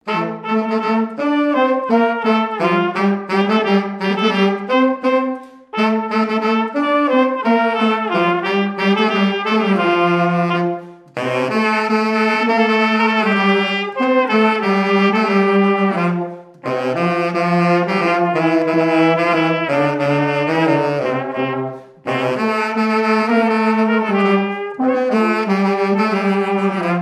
Marche de noce No 4
Champagné-les-Marais
Pièce musicale inédite